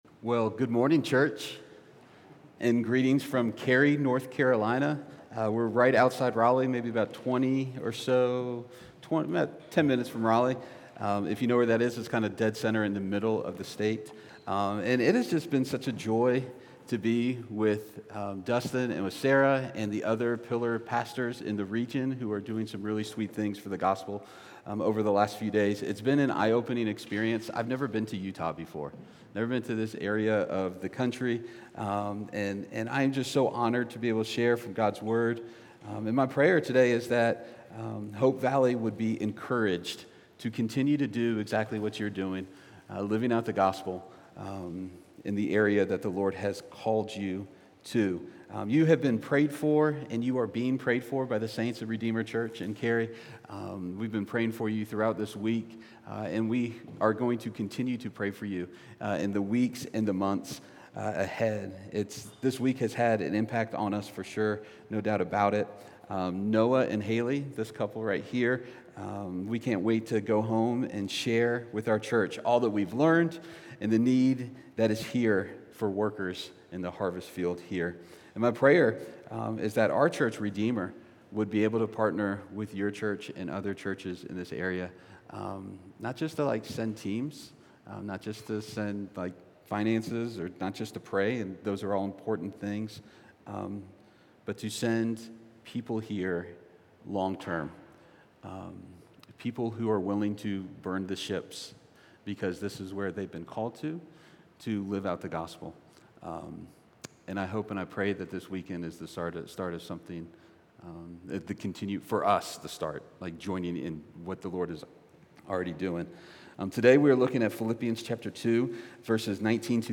A message from the series "Stand-Alone Sermons."